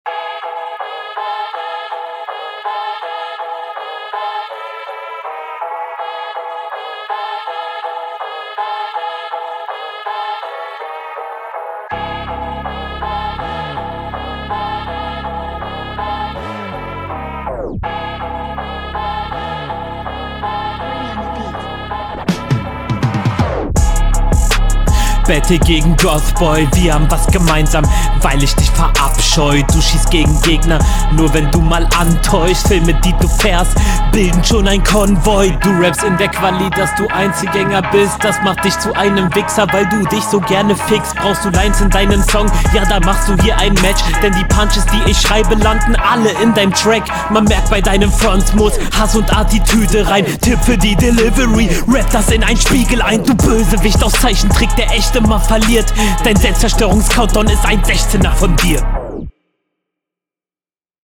stimmeinsatz etwas drüber und gleichzeitig drunter (mach da ne line draus), betonungen kommen aber hart …
Du flowst recht einsilbig. Der Einstieg ist schon strange und wirkt verspätet.
Ganz komischer einstieg, kann manchmal gut klingen ist hier aber leider zu deinem Nachteil.